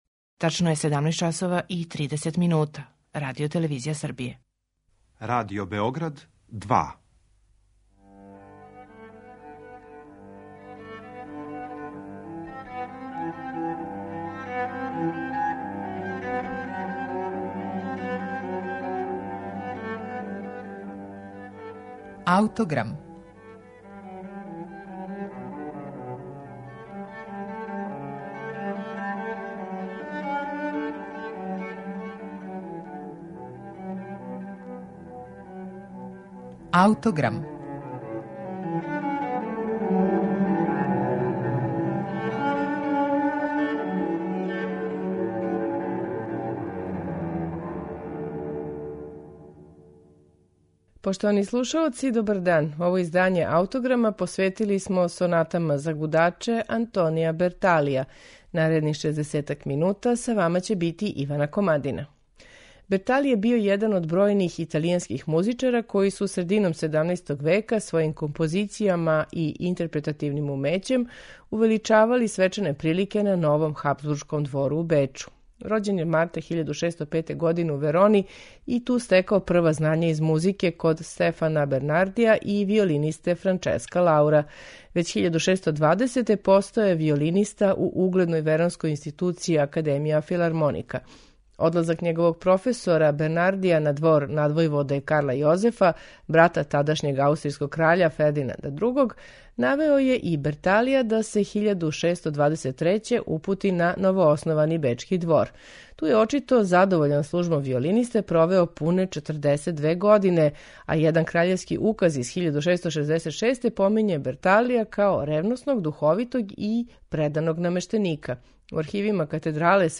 Сонате за гудаче Антонија Берталија
на оригиналним барокним инструментима
виолина
виола да гамба